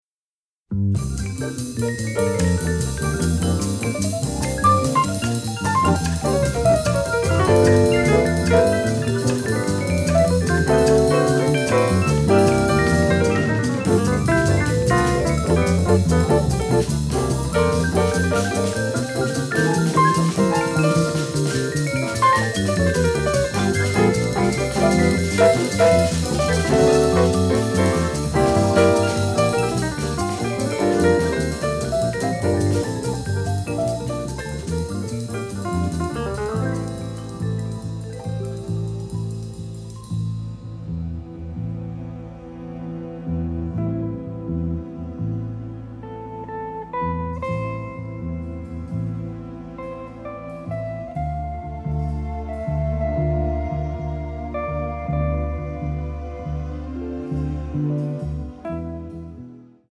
great exotica and pop tunes